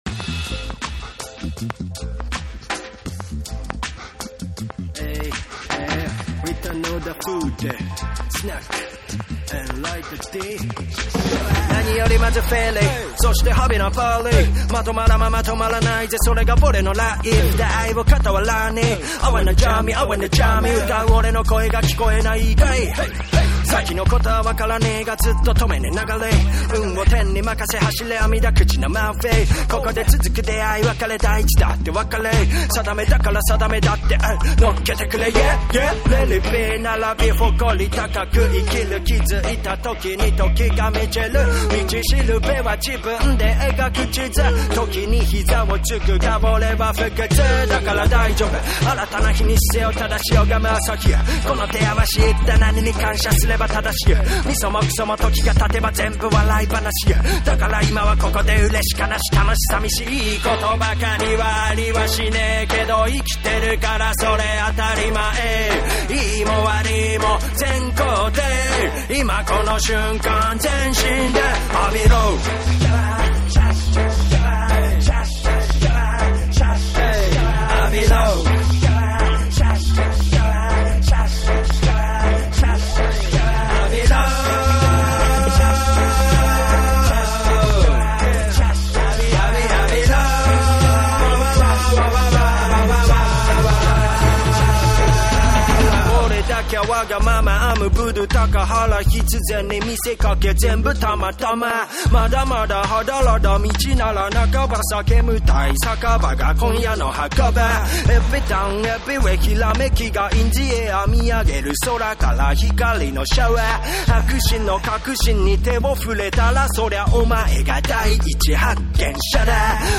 JAPANESE / REGGAE & DUB / NEW RELEASE(新譜)